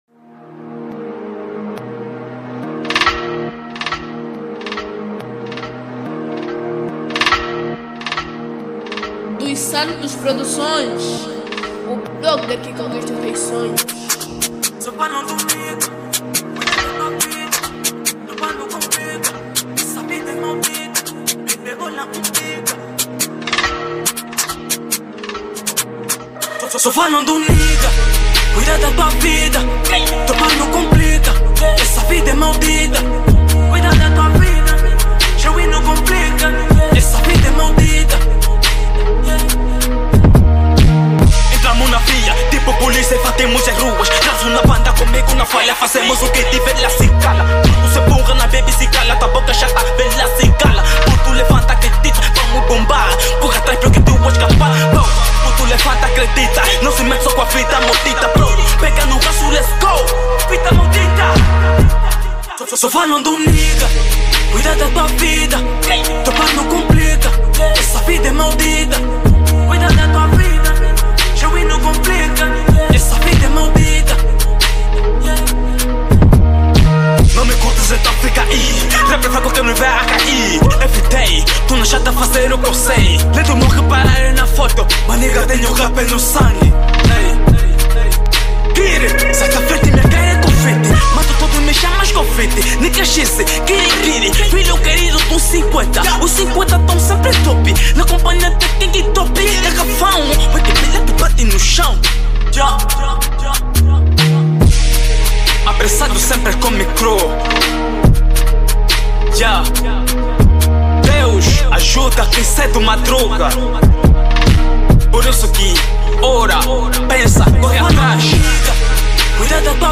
Categoria Rap